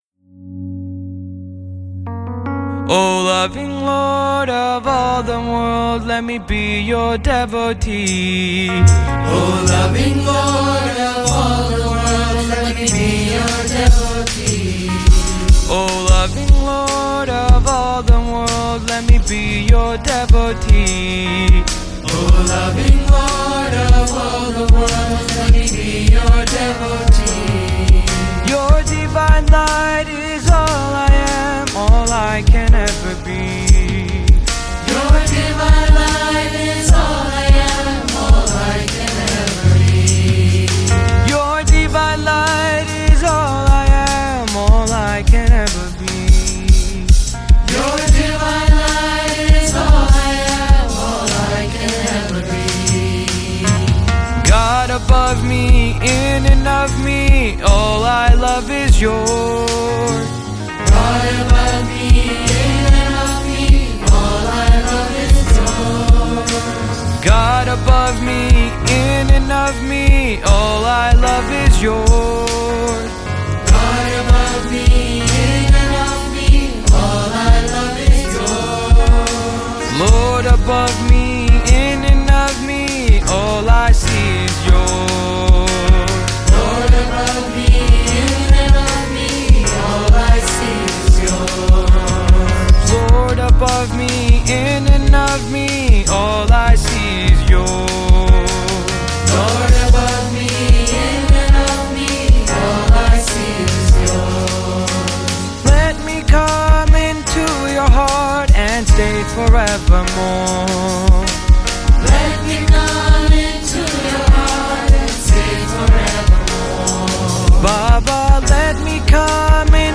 1. Devotional Songs
Major (Shankarabharanam / Bilawal)
8 Beat / Keherwa / Adi
4 Pancham / F
1 Pancham / C
Lowest Note: S / C
Highest Note: D2 / A